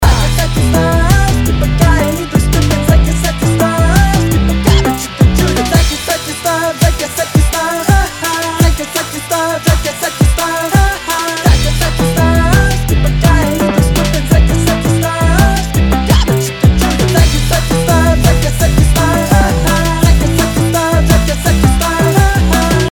Подскажите пожалуйста как приблизить полностью трек? Не могу понять в чем проблема, делаю сведение и мастеринг но в итоге звук дальше по сравнению с другими треками.